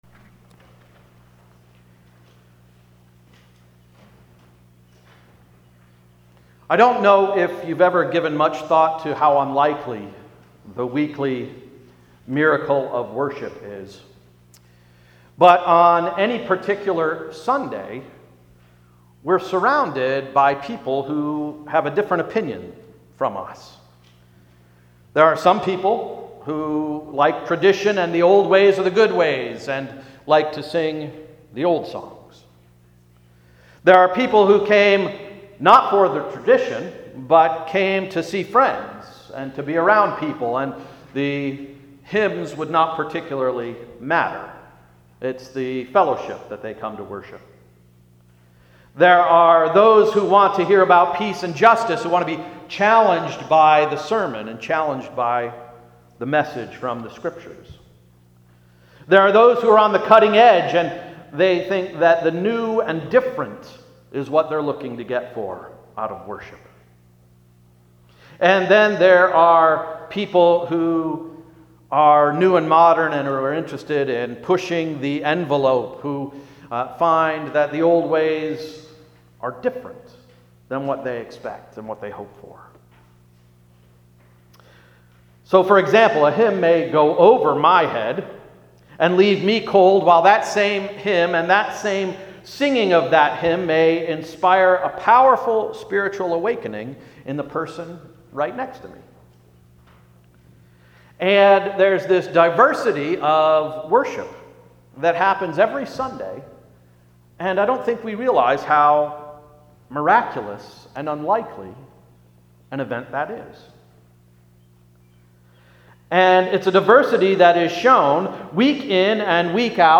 “Spirit Sightings” — Sermon of June 4, 2017